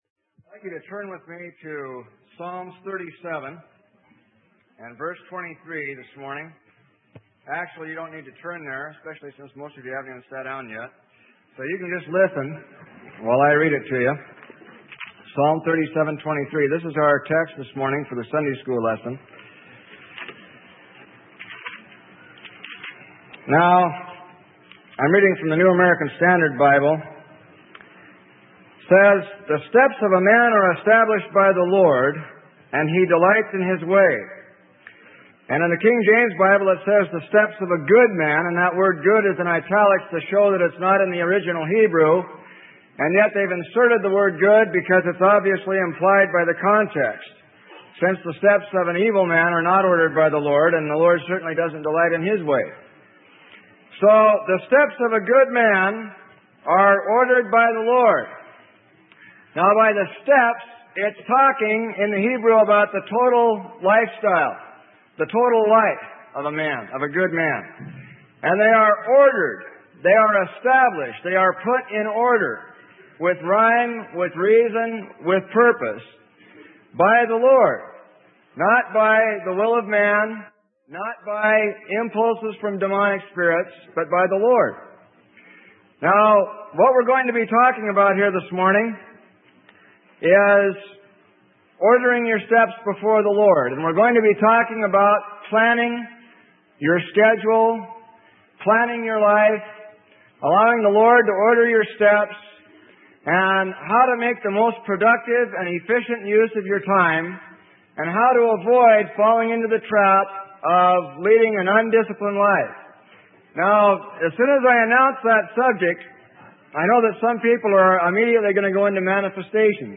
Sermon: Ordered by the Lord - Freely Given Online Library